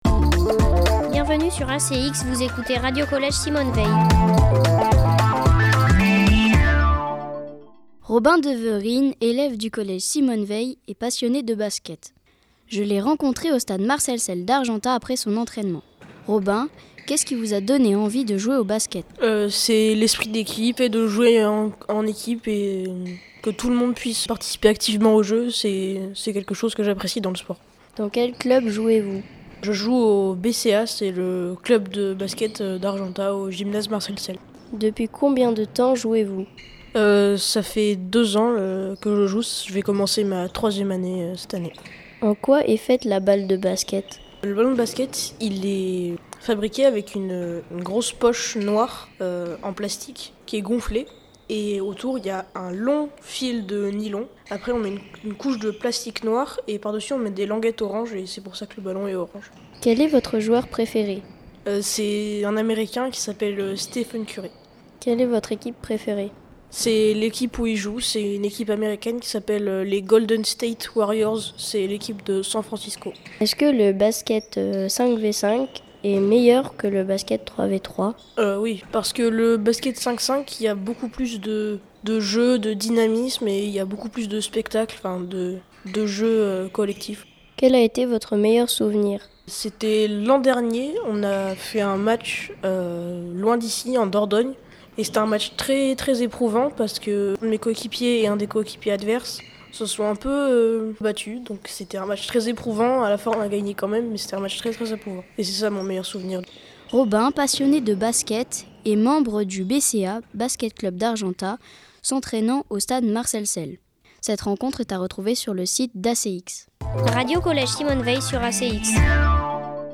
Genre : Abstract.